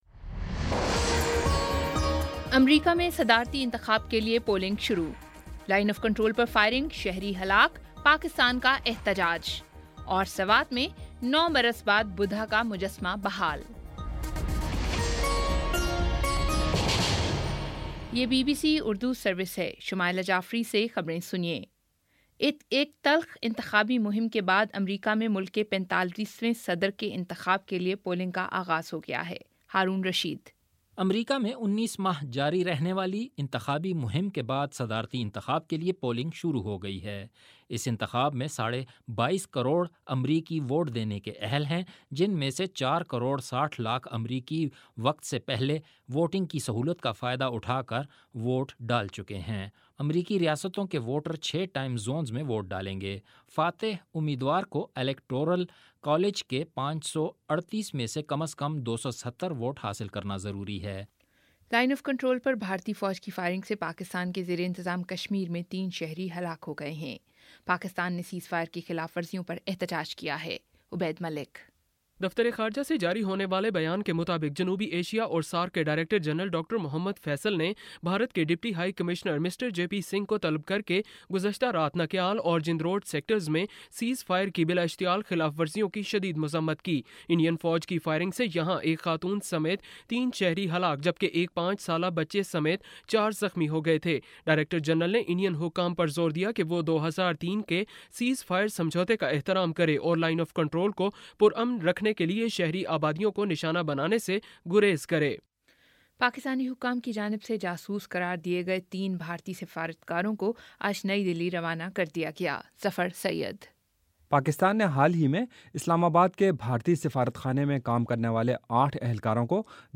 نومبر 08 : شام چھ بجے کا نیوز بُلیٹن